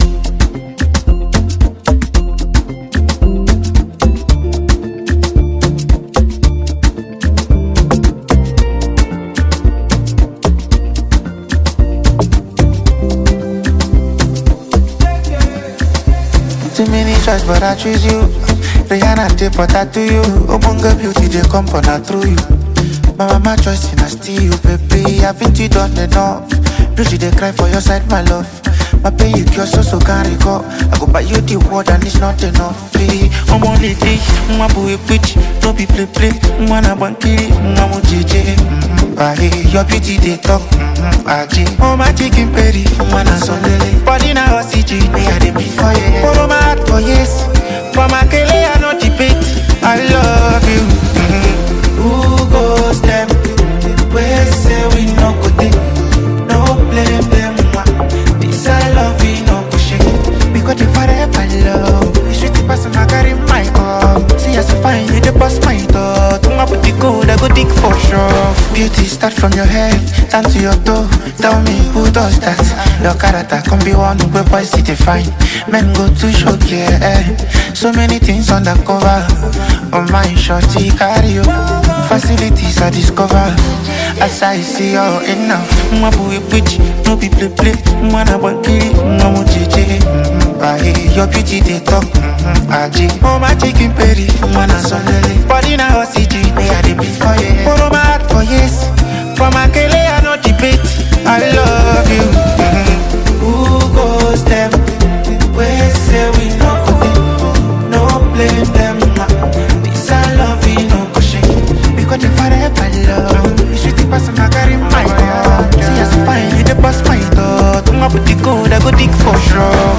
soulful new single
With smooth vocals and heartfelt lyricism